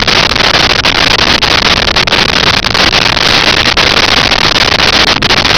Sfx Holosteady Loop1
sfx_holosteady_loop1.wav